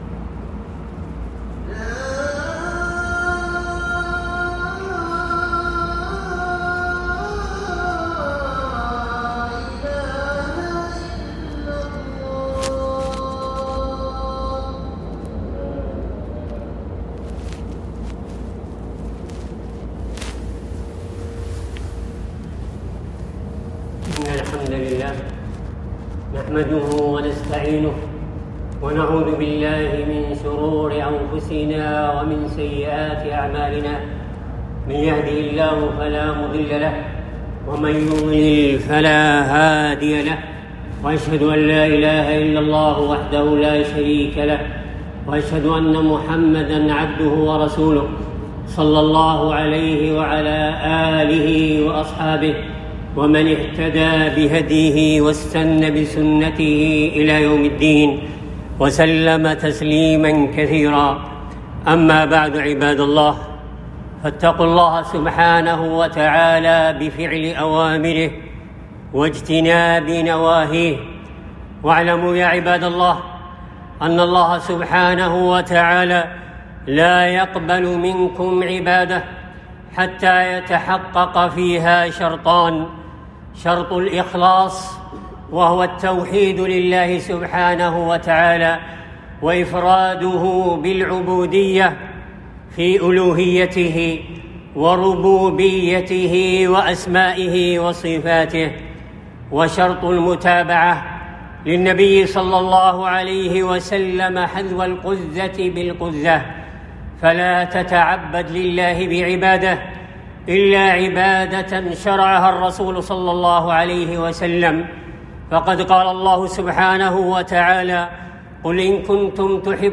أهمية الاتباع والتحذير من البدع و- لخطبة الثانية في اهتمام الإسلام بالنفس والصحة